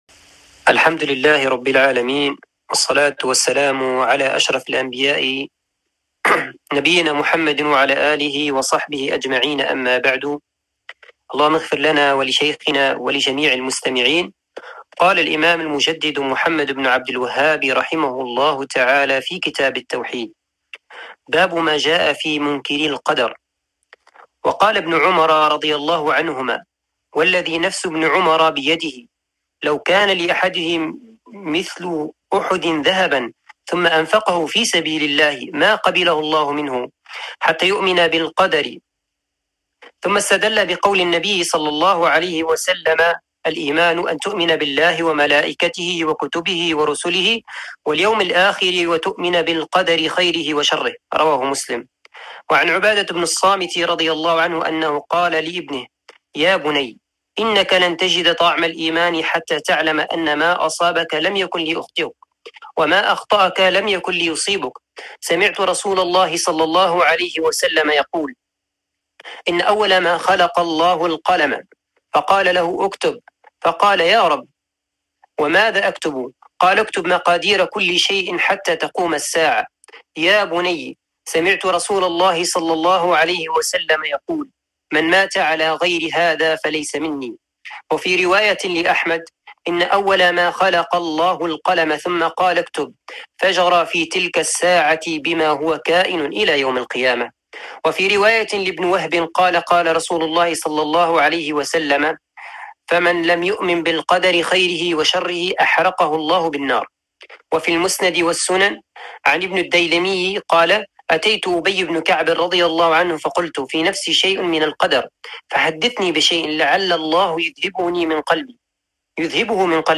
درس شرح كتاب التوحيد (50)